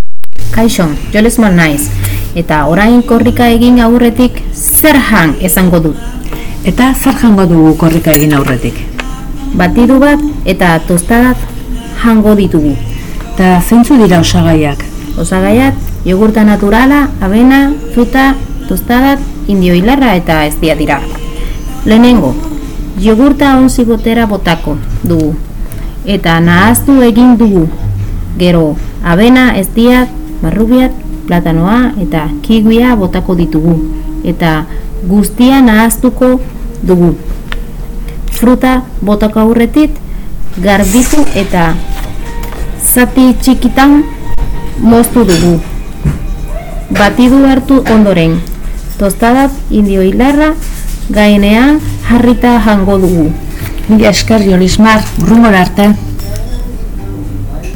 Arkakuso irratiko estudioa zabal zabalik dago ikasle, irakasle eta gurasoentzat.